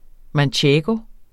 Udtale [ manˈtjεːgo ]